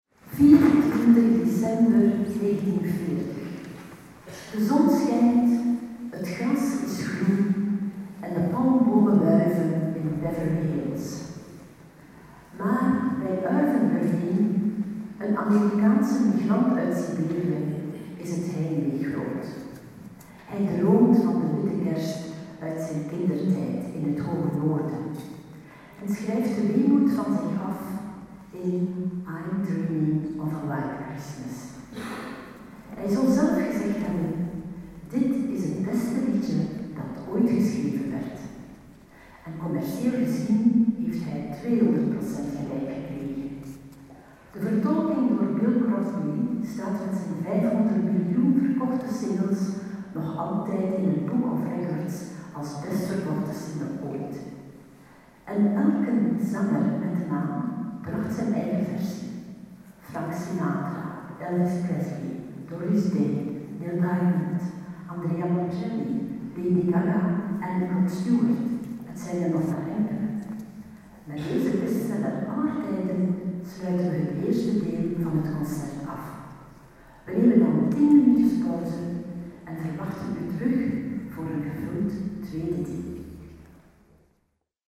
Kerstconcert
Piano en orgel